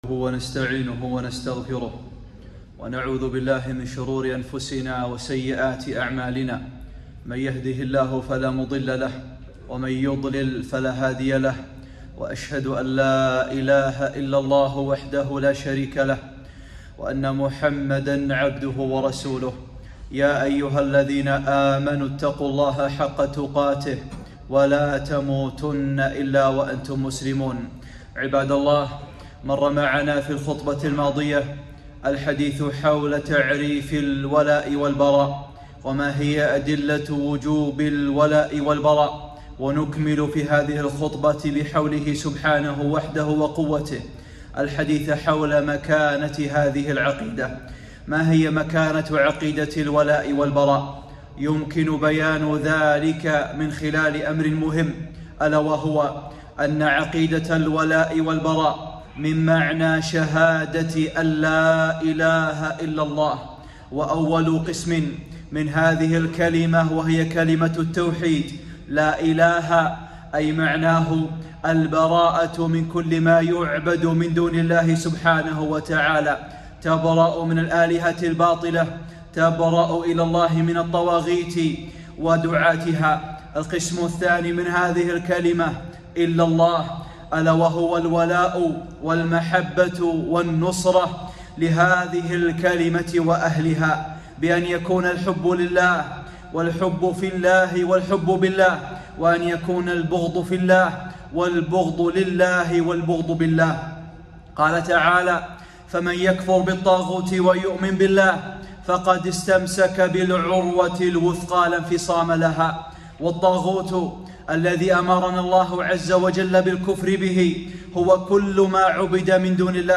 ( 2 ) خطبة الولاء والبراء || أقسامه وأسباب الانحراف